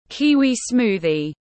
Sinh tố kiwi tiếng anh gọi là kiwi smoothie, phiên âm tiếng anh đọc là /ˈkiː.wiː ˈsmuː.ði/
Kiwi smoothie /ˈkiː.wiː ˈsmuː.ði/